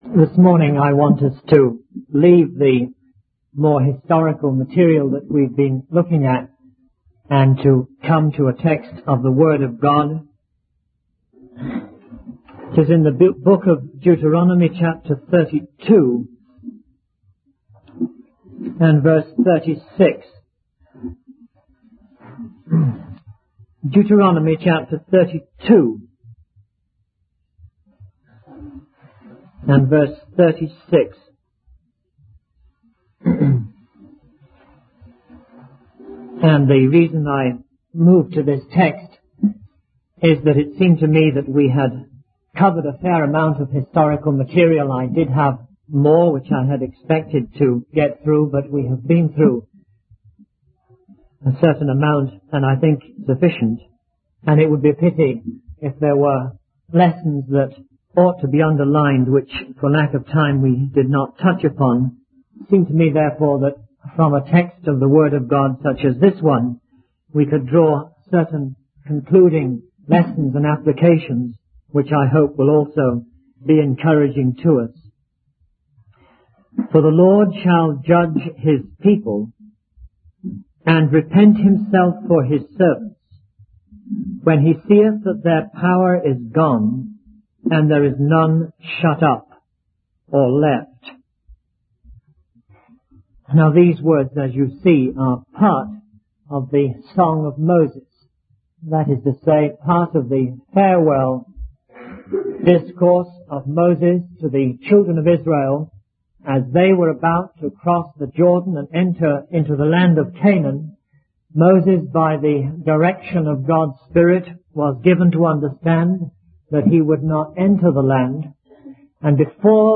In this sermon, the speaker begins by acknowledging that they have covered a lot of historical material and now wants to focus on a text from the book of Deuteronomy. The chosen text is from Deuteronomy 32:36, which is part of the song of Moses. The speaker explains that this verse speaks about God judging his people and repenting for them when they are weak and helpless.